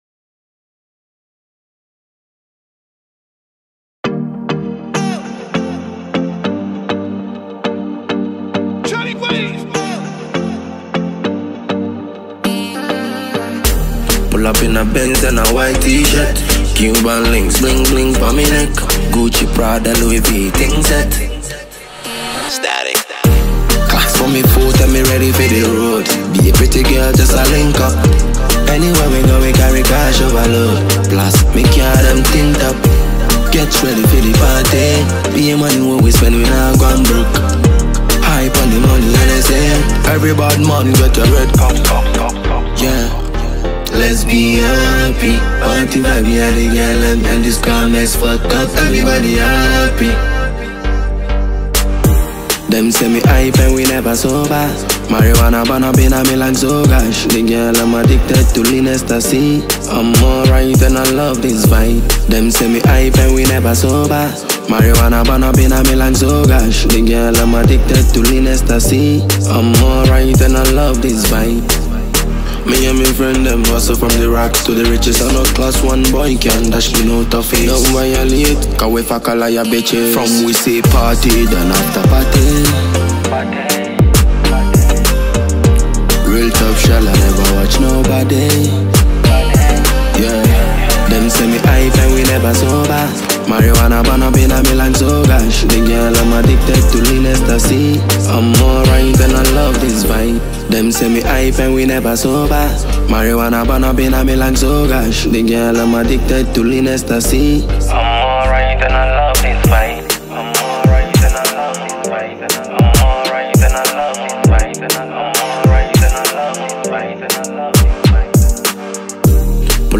Ghana Music
dancehall banger